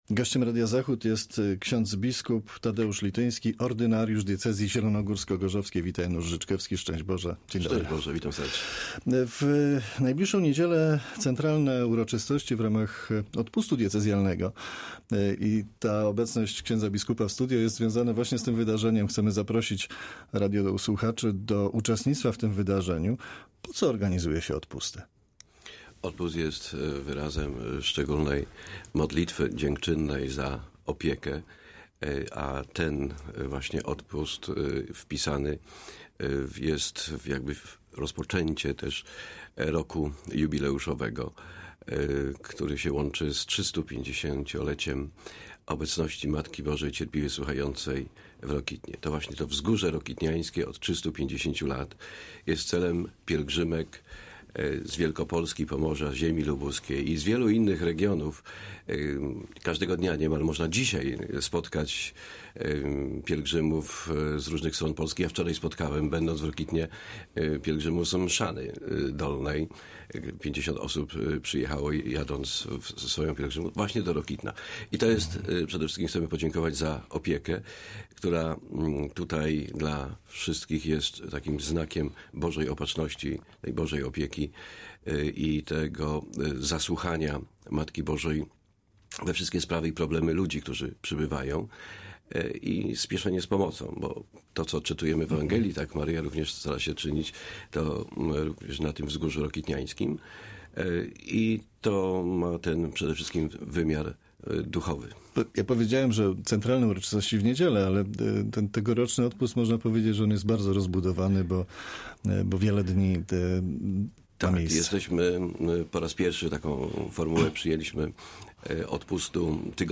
Z księdzem biskupem, ordynariuszem diecezji zielonogórsko-gorzowskiej